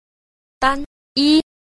3. 單一 – dānyī – đơn nhất (đơn độc)